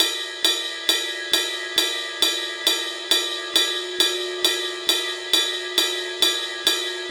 Jfx Ride.wav